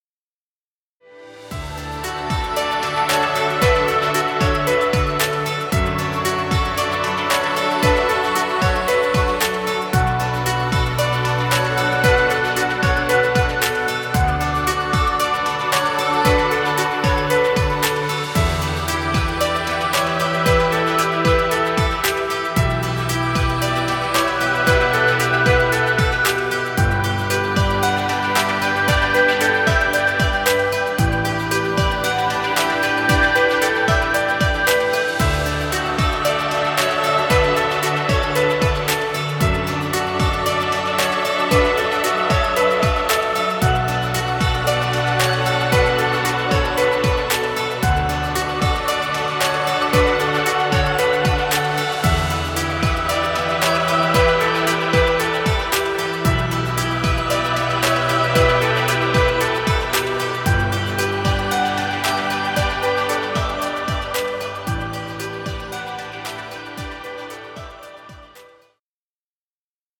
Chillout music.